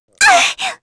Pansirone-Vox_Damage_kr_01.wav